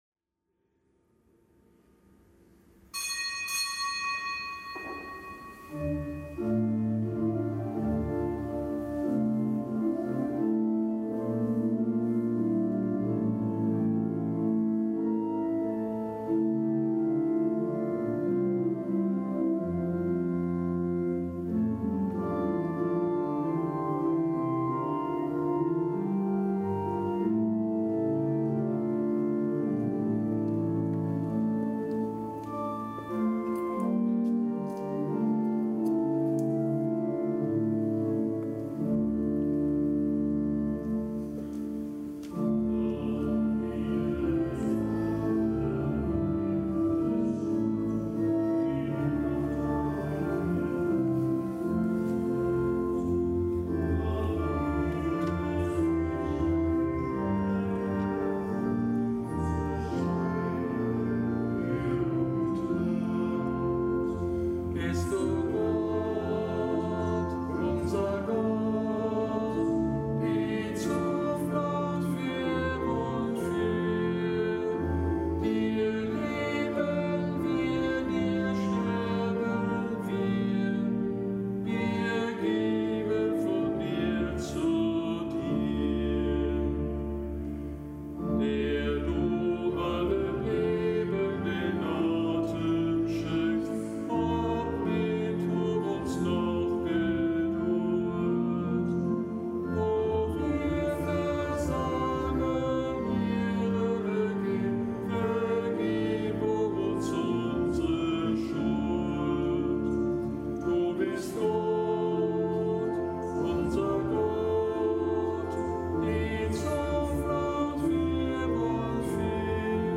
Kapitelsmesse am Mittwoch der siebenundzwanzigsten Woche im Jahreskreis
Kapitelsmesse aus dem Kölner Dom am Mittwoch der siebenundzwanzigsten Woche im Jahreskreis.